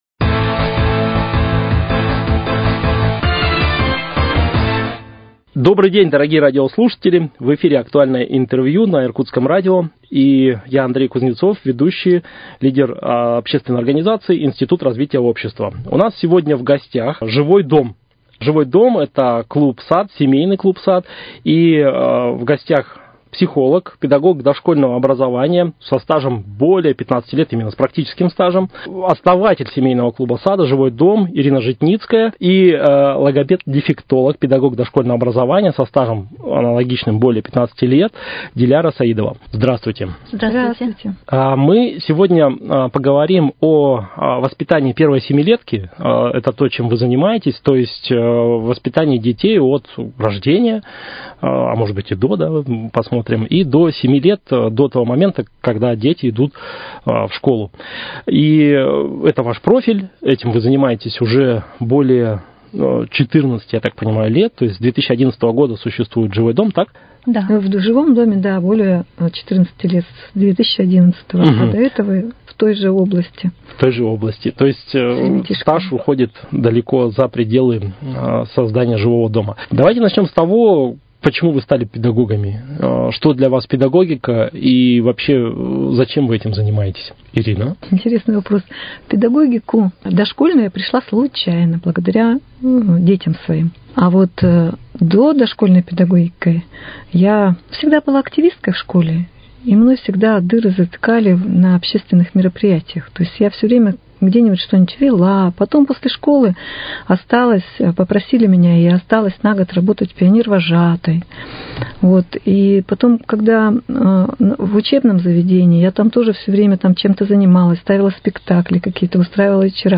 Актуальное интервью: О воспитании детей от рождения до 7 лет